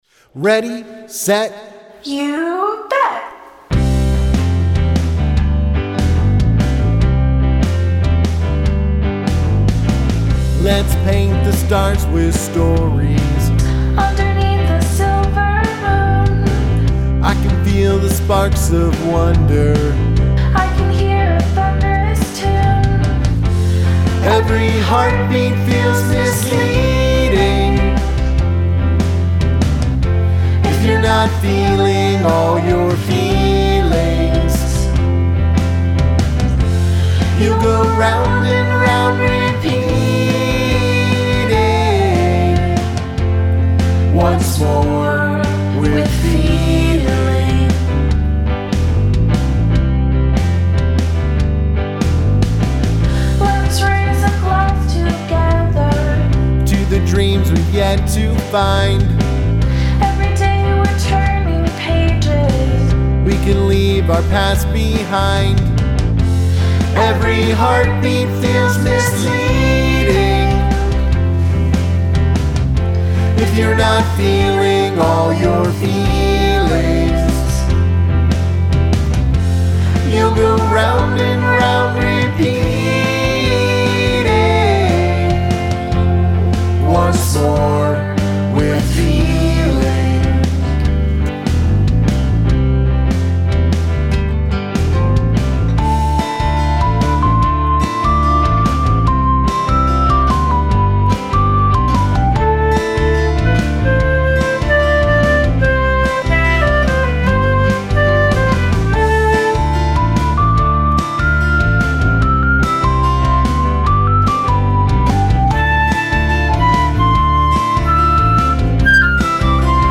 She sang lead and also played the flute in the bridge.